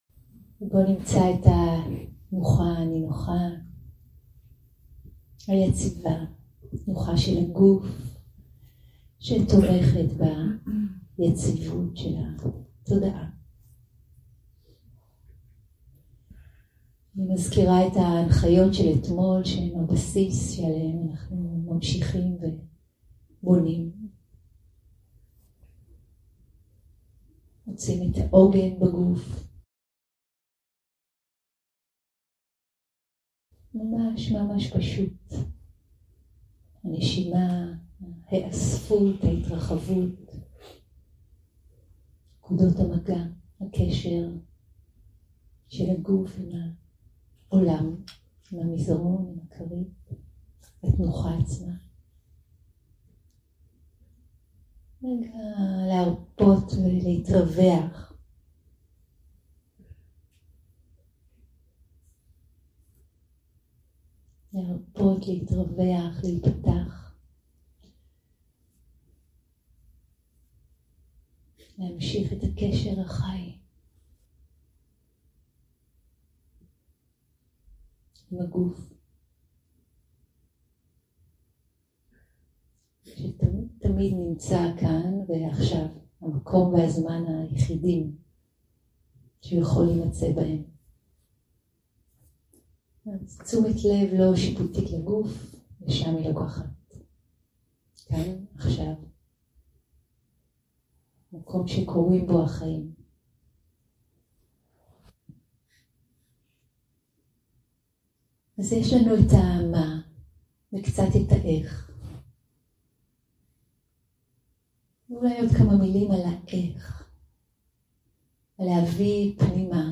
יום 3 - הקלטה 7 - צהריים - מדיטציה מונחית - הנחיות רכות
Dharma type: Guided meditation שפת ההקלטה